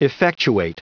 Prononciation du mot effectuate en anglais (fichier audio)
Prononciation du mot : effectuate